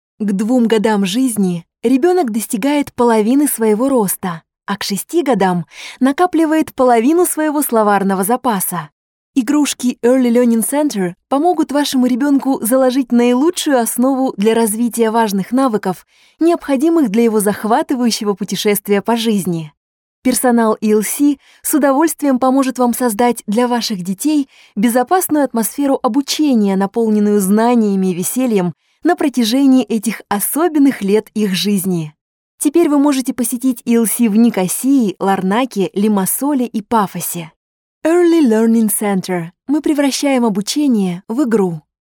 商业广告